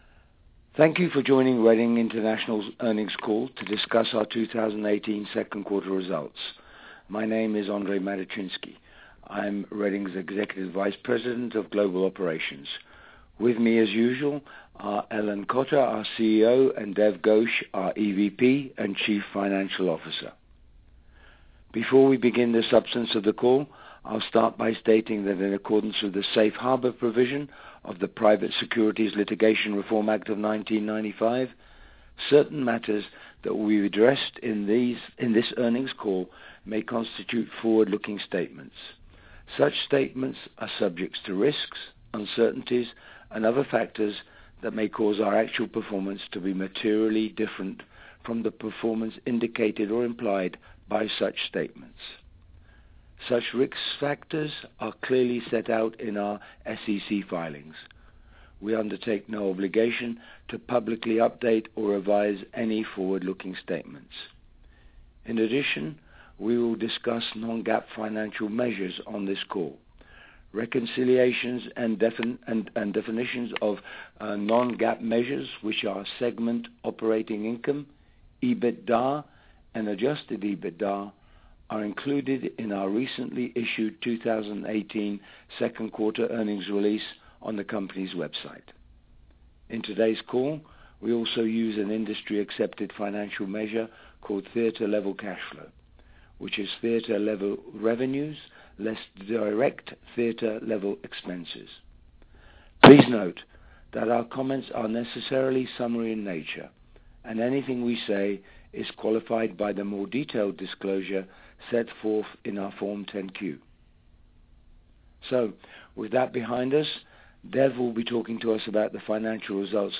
Question and answer session will follow the formal remarks.